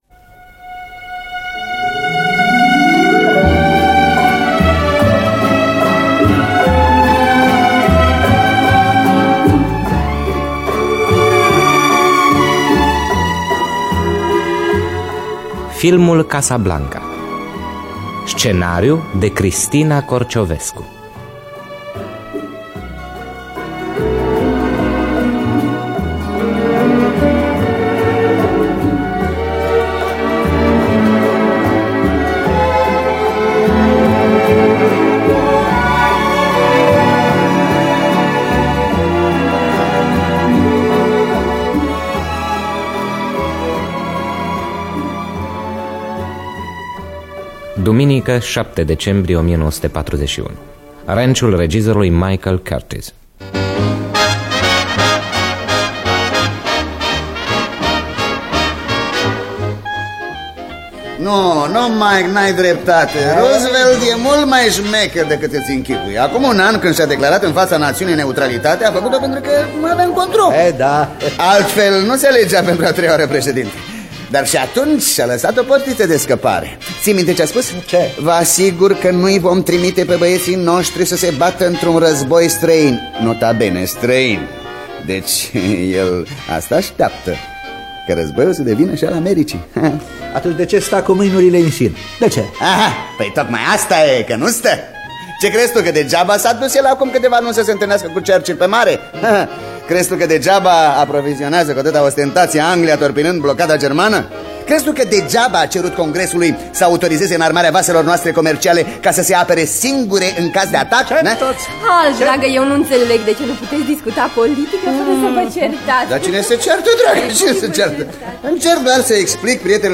Biografii, Memorii: Filmul Casablanca (1983) – Teatru Radiofonic Online